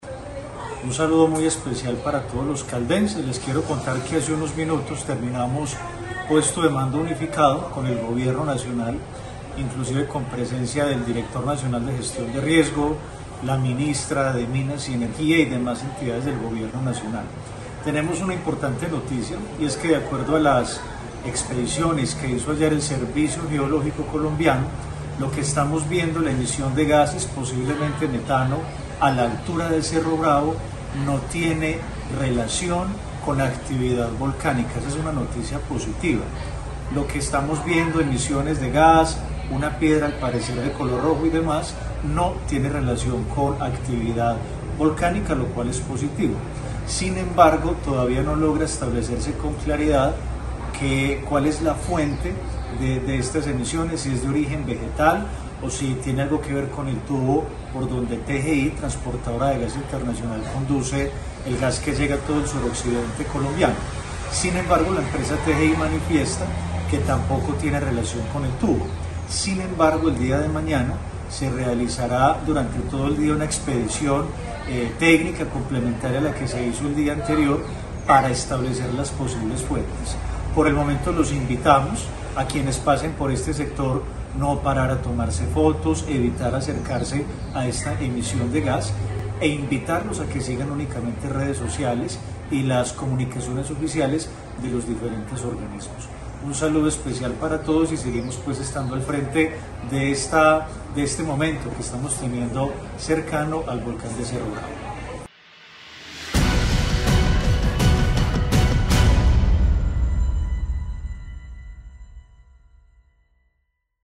Audio-gobernador-de-Caldas-Luis-Carlos-Velasquez-Cardona.mp3